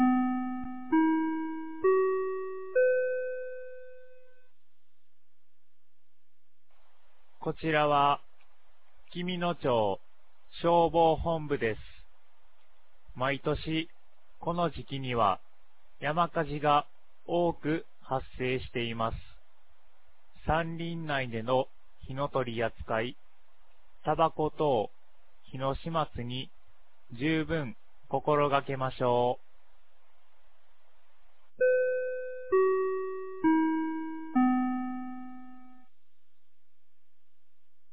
2025年05月31日 12時31分に、紀美野町より全地区へ放送がありました。